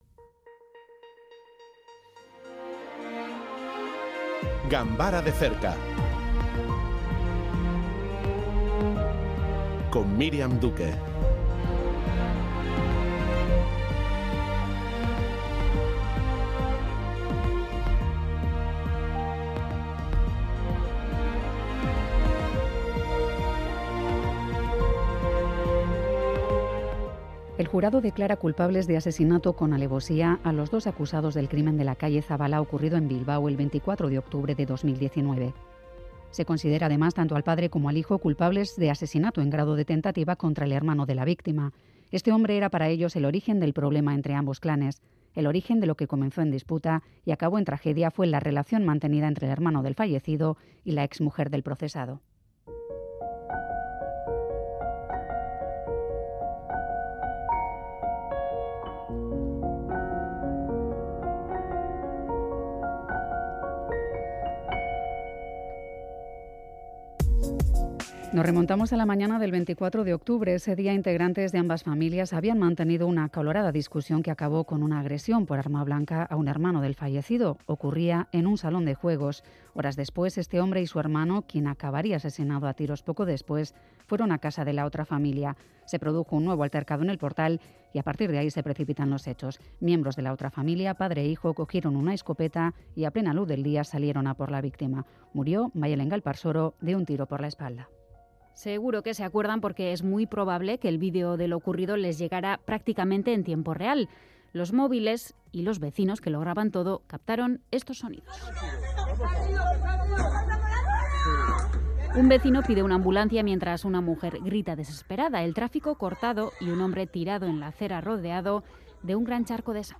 Informativo que analiza con detalle temas de actualidad